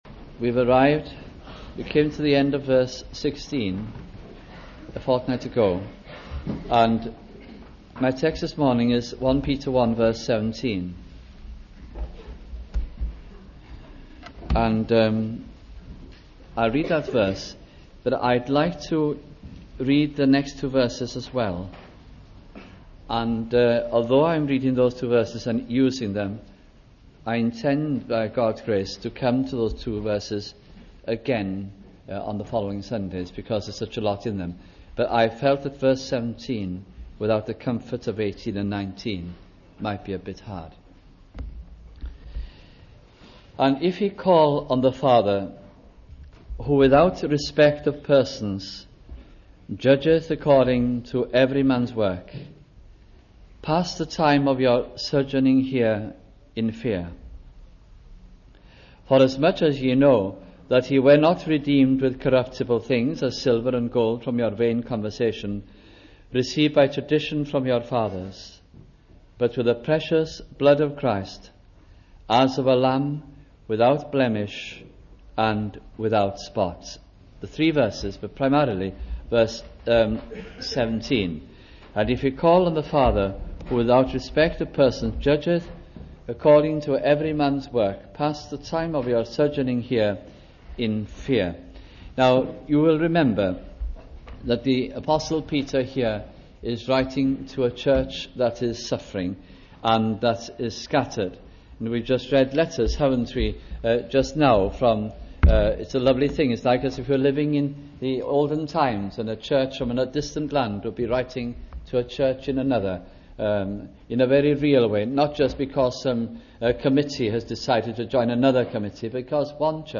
» First Epistle of Peter Series 1982 - 1983 » sunday morning messages from this gracious epistle